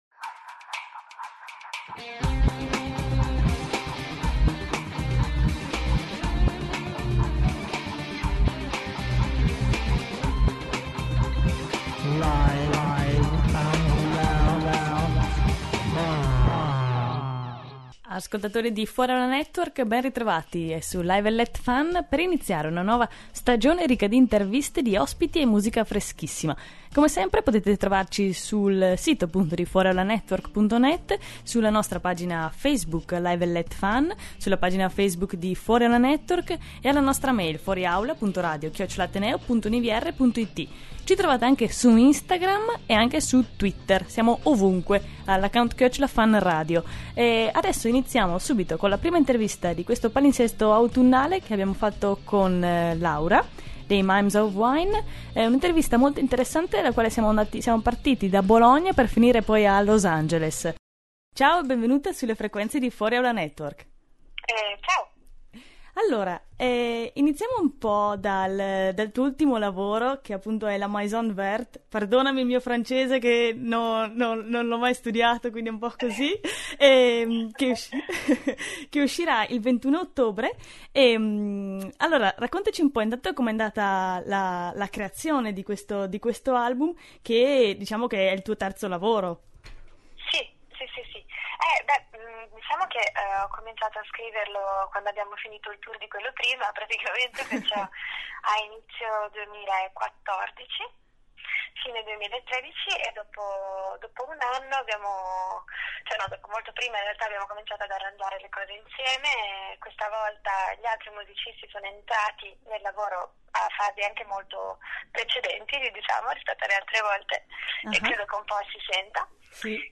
Anche noi di Live And Let FAN ripartiamo nel nuovo palinsesto con tante interviste!